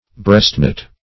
Definition of breastknot.
breastknot.mp3